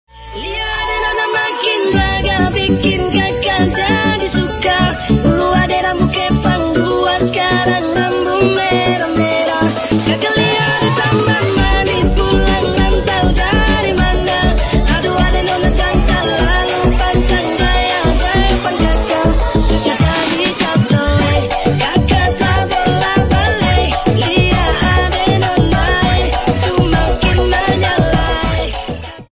pemadam kebakaran sound effects free download